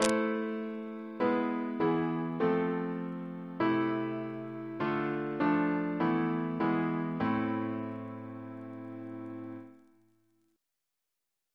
Single chant in A minor Composer: George A. Macfarren (1813-1887) Reference psalters: ACB: 115; ACP: 156; OCB: 219